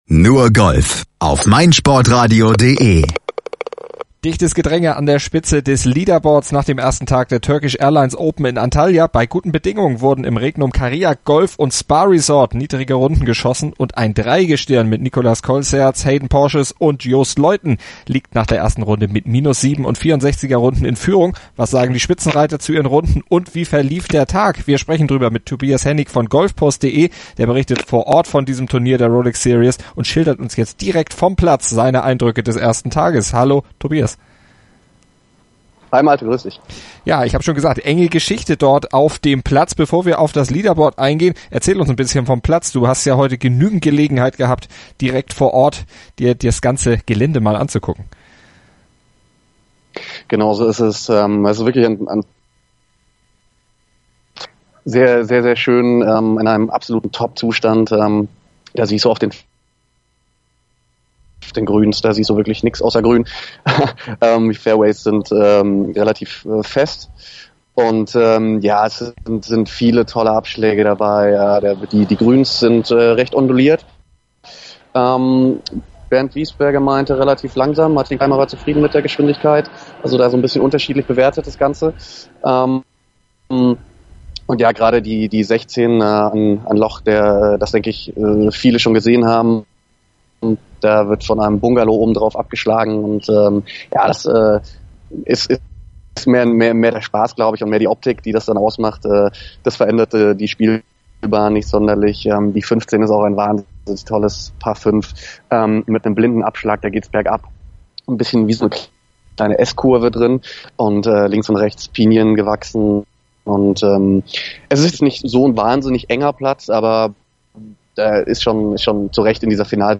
Der berichtet vor Ort von diesem Turnier der Rolex Series und schildert und jetzt direkt aus dem Pressezentrum auf dem Platz seine Eindrücke des ersten Tages.